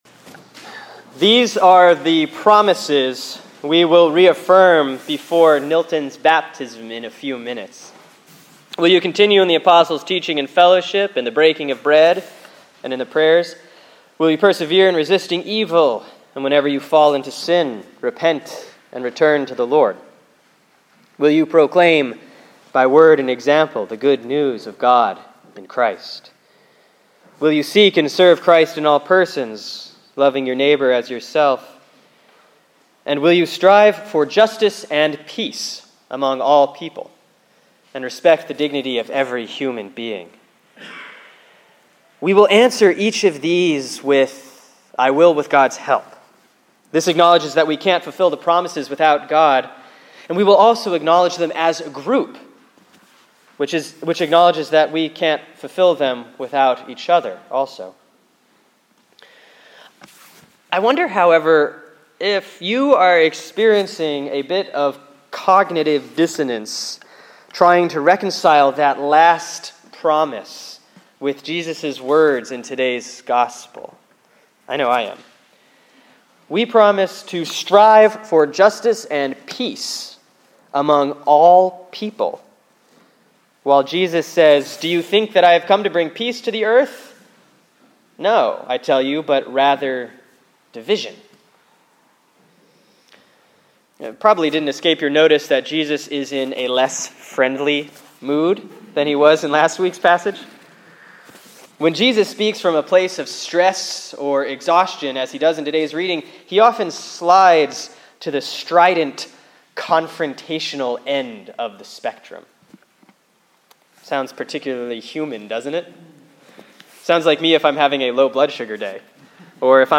(Sermon for Sunday, August 18, 2013 || Proper 15C || Luke 12:49-56)